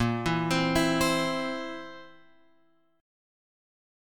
A# Suspended 2nd Flat 5th